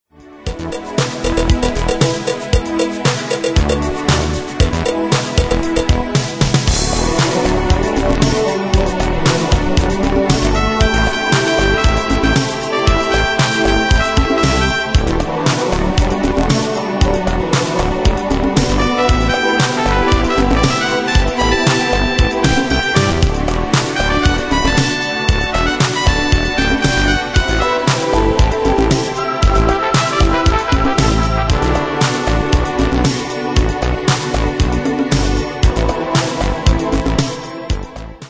Demos Midi Audio
Style: Dance Année/Year: 1986 Tempo: 116 Durée/Time: 4.07
Pro Backing Tracks